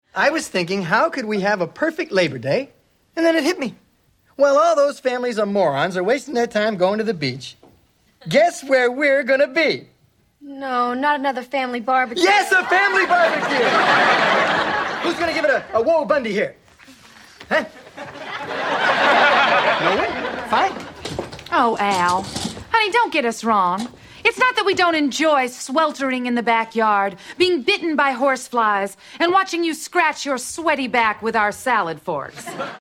There are a few episodes with a speed/pitched up (?) voice of Al.
Here are two samples, one sounds “normal” the other one like a speed up verision (but it was not modified)
There is a speedup from about 4%.